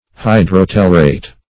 Search Result for " hydrotellurate" : The Collaborative International Dictionary of English v.0.48: Hydrotellurate \Hy`dro*tel"lu*rate\, n. (Chem.) A salt formed by the union of hydrotelluric acid and the base.